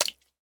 minecraft / sounds / entity / fish / flop1.ogg
flop1.ogg